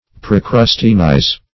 Search Result for " procrusteanize" : The Collaborative International Dictionary of English v.0.48: Procrusteanize \Pro*crus"te*an*ize\, v. t. [imp.
procrusteanize.mp3